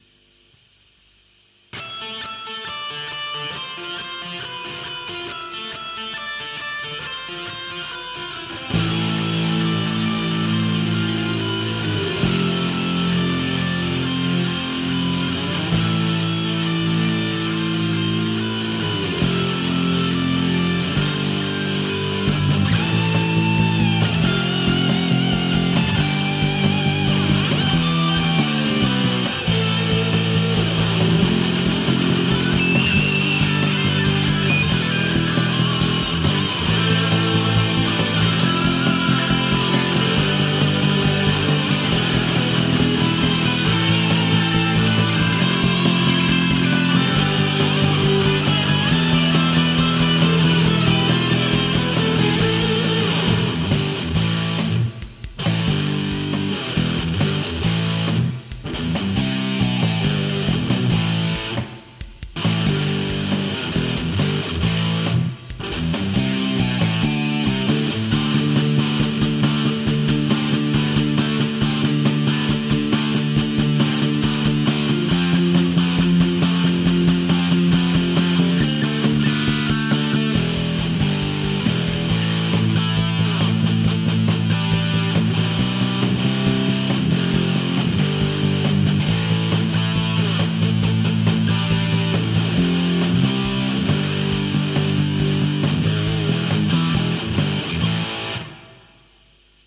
DAW : Cakewalk (with MT POWER DrumKit2、SI-Bass guitar)